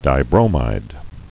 (dī-brōmīd, -mĭd)